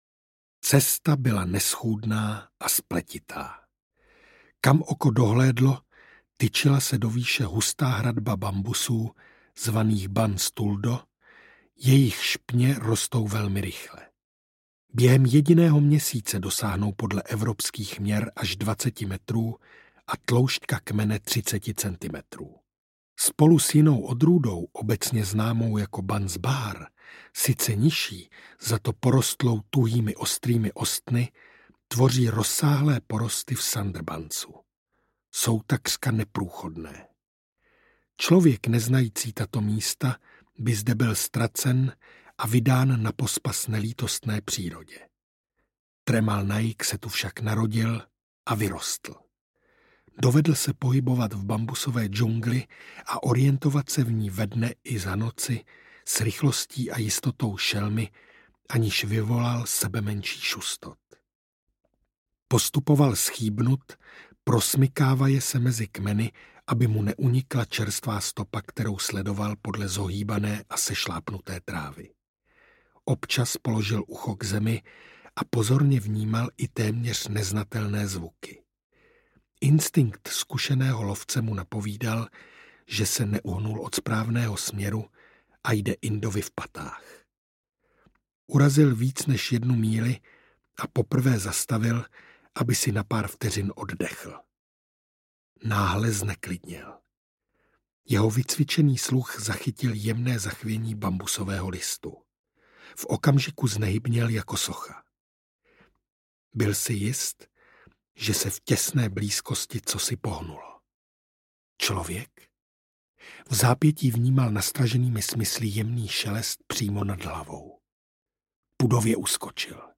Sandokan II: Tajemství černé džungle audiokniha
Ukázka z knihy
Vyrobilo studio Soundguru.